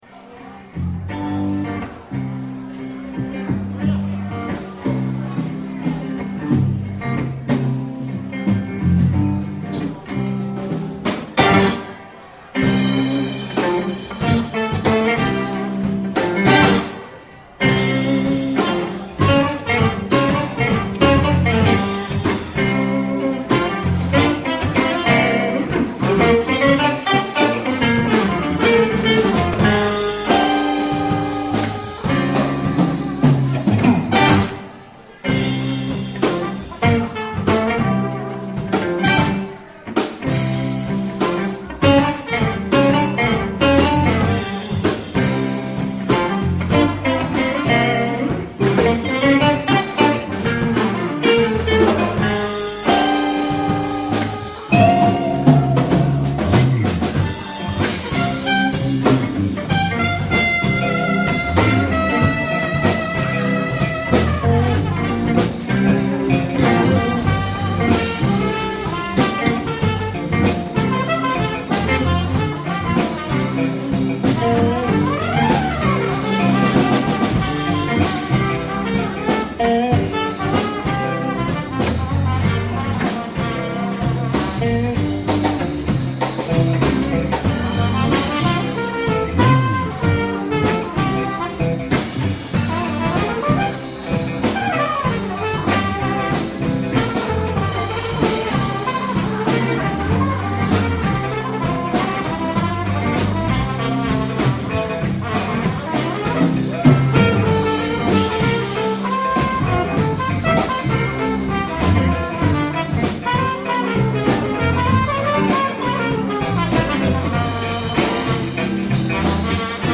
trumpet
guitar